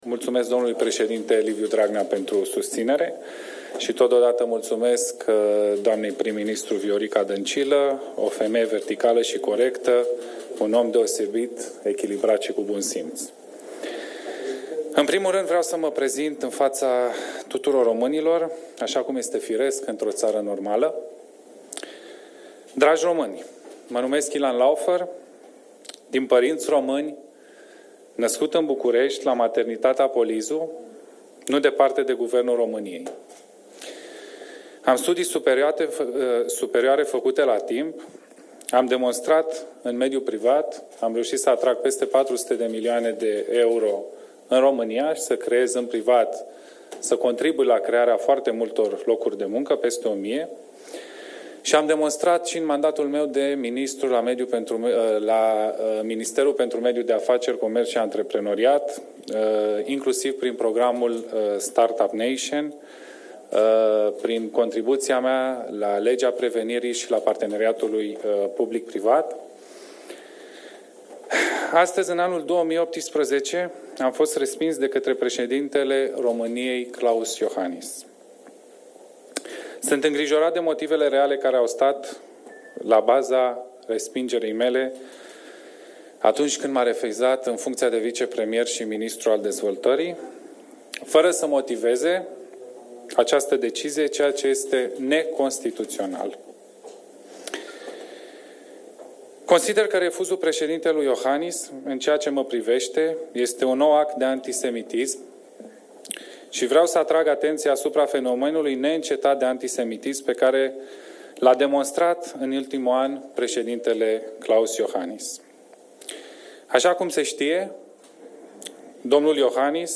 Fost ministru pentru Mediul de Afaceri in cabinetul Mihai Tudose si coordonator al programului Start-up Nation Romania, Ilan laufer a facut o declaratie la finalul şedinţei Biroului Politic Naţional al PSD.